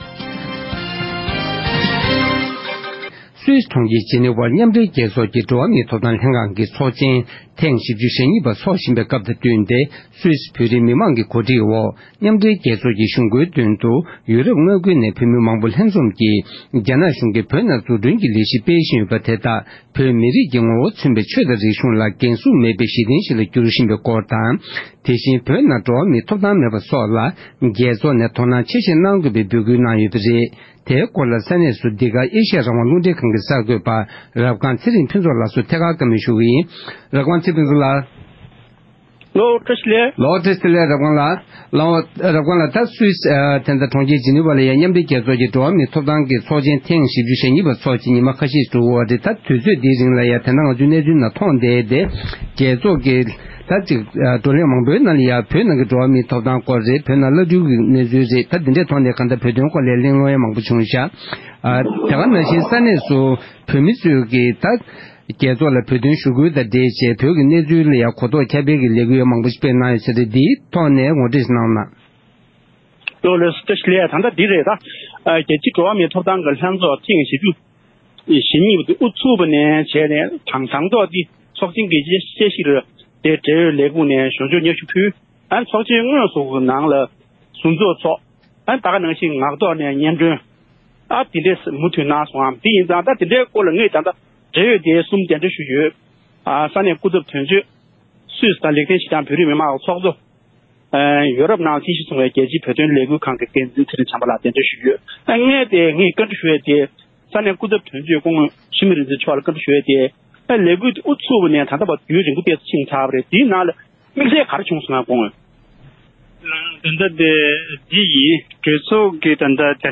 ས་གནས་ནས་ཐད་ཀར་ངོ་སྤྲོད་གནང་བར་གསན་རོགས།